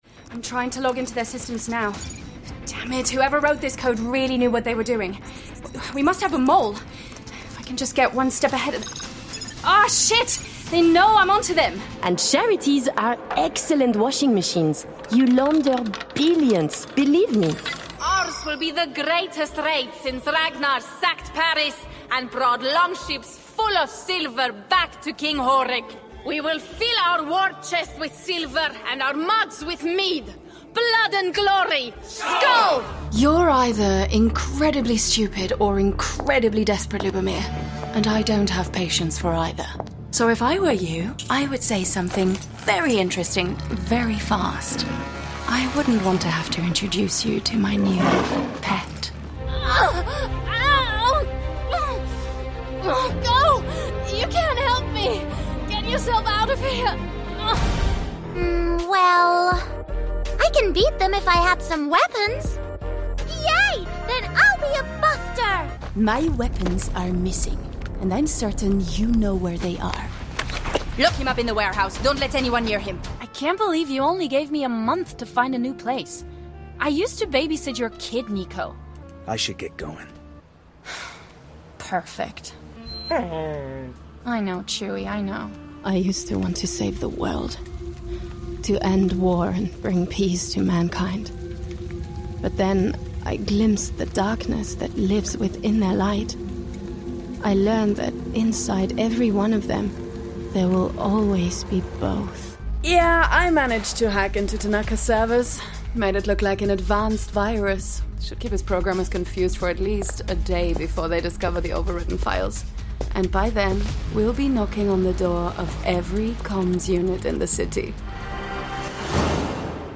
Video Games
My voice is relatable, contemporary and youthful with a warm and textured sound.